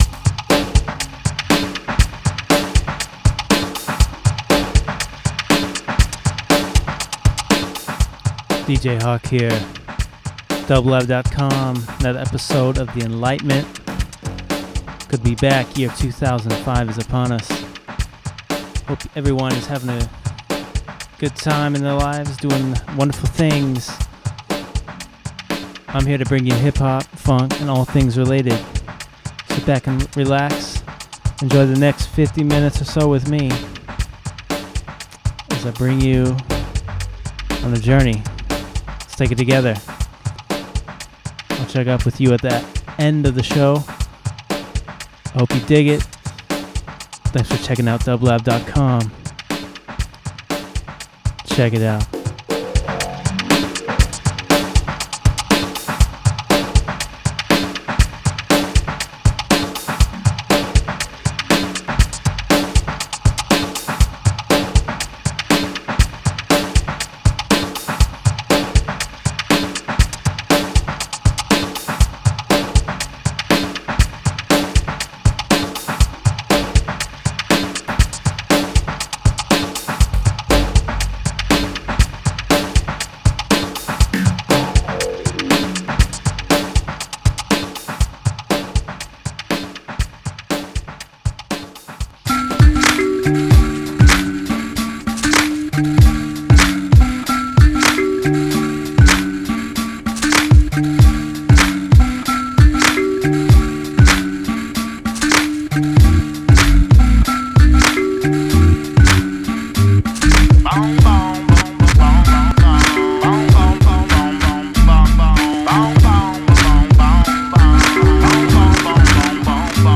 mix
Funk/Soul Hip Hop